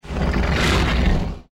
File:Giant gila monster roar.mp3
Giant_gila_monster_roar.mp3